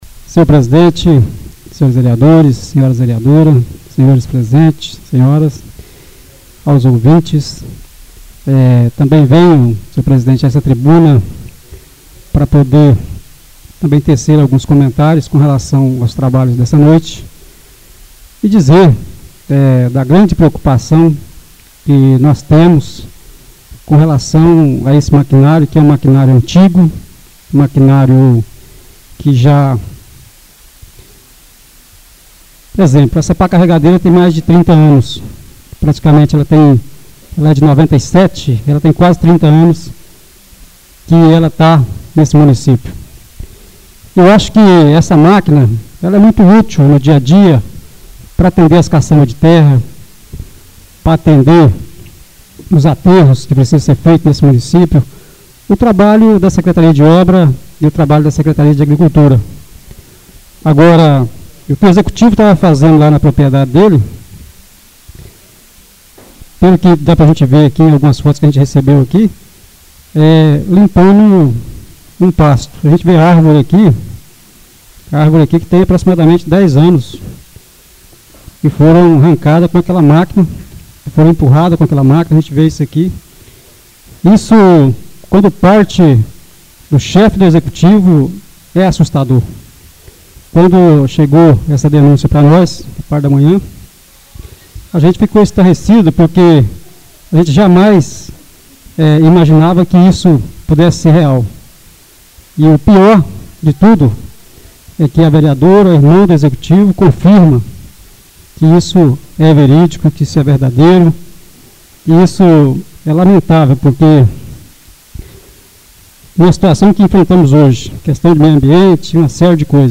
Oradores das Explicações Pessoais (26ª Ordinária da 3ª Sessão Legislativa da 6ª Legislatura)